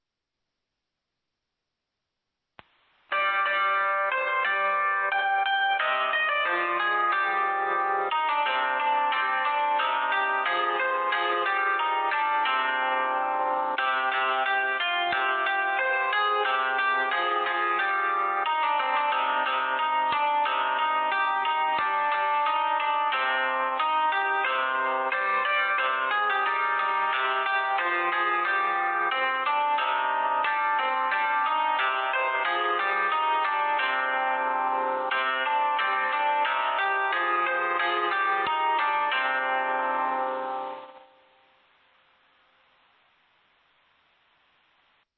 紀宝町防災無線情報